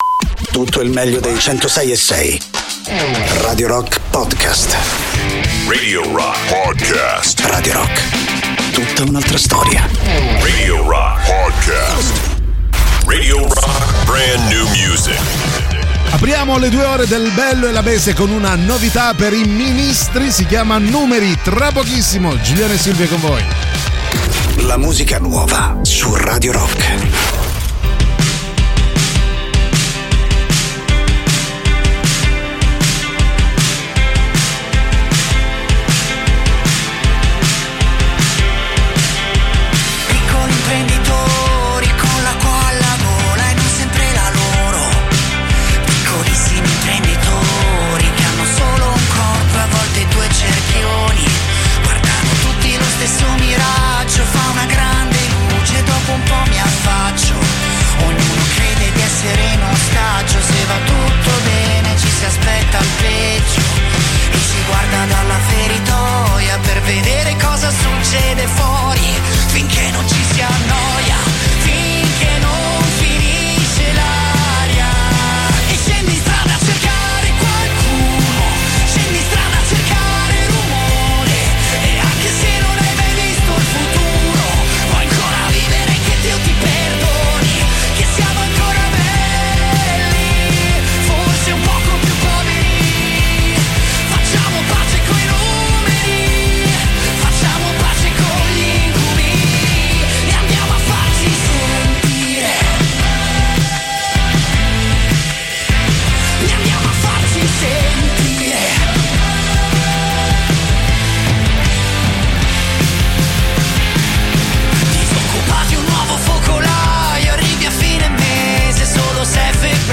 in diretta sui 106.6 di Radio Rock dal Lunedì al Venerdì dalle 13.00 alle 15.00